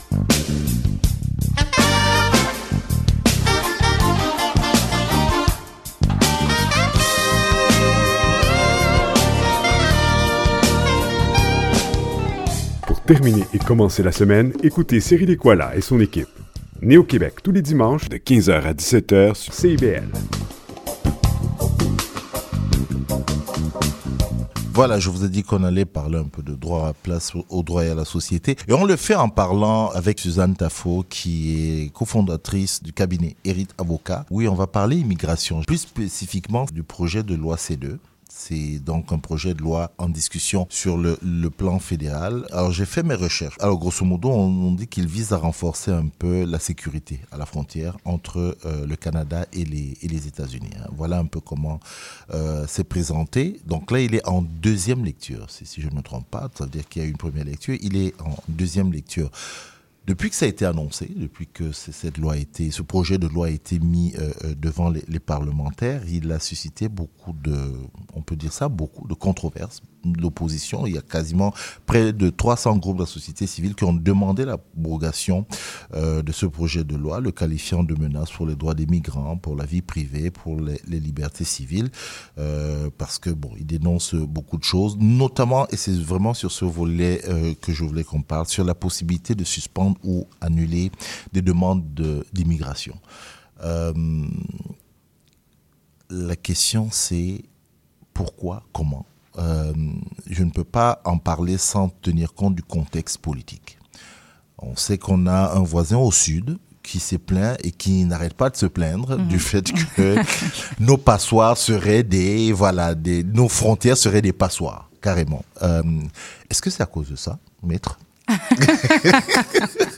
au cours de l’émission radio de Neoquébec